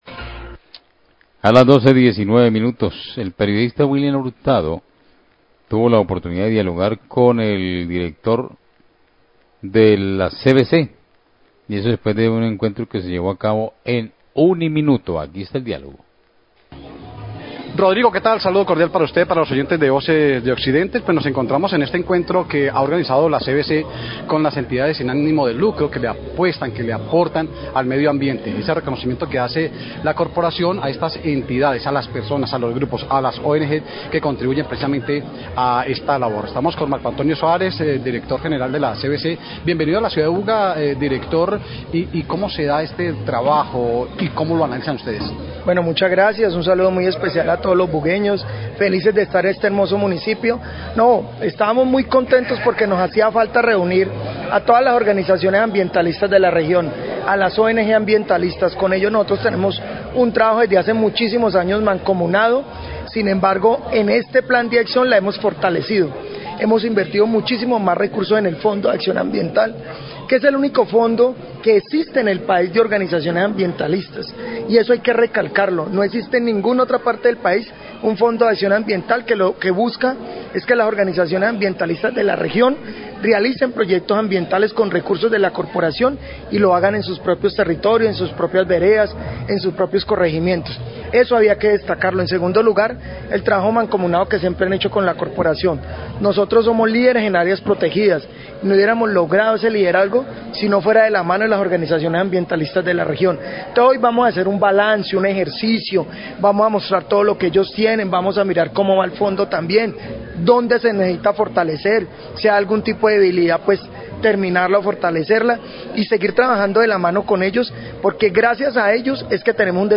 Dir. CVC habla de reunión con organizaciones ambientalistas de la región
Radio